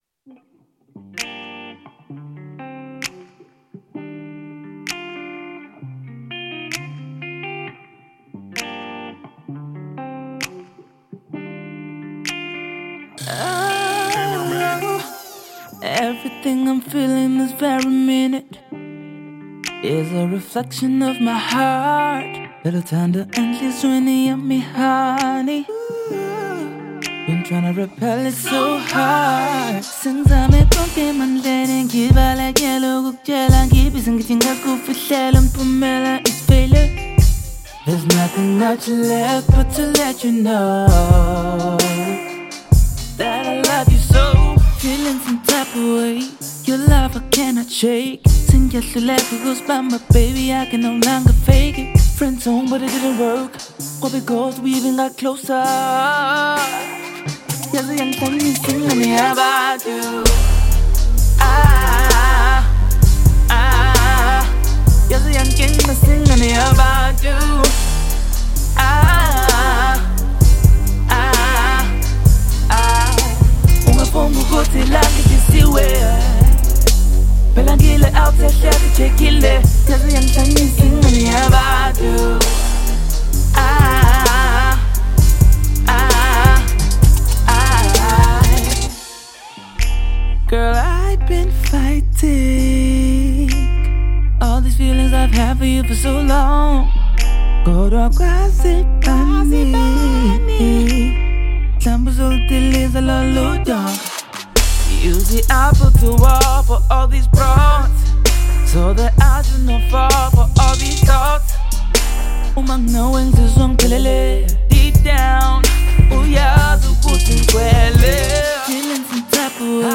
the song is very silky and smooth